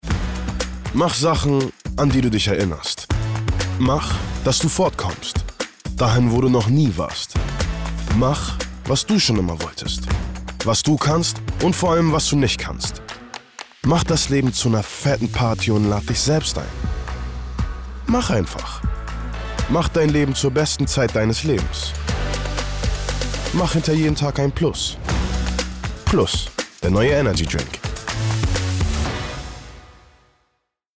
markant, dunkel, sonor, souverän, plakativ
Mittel minus (25-45)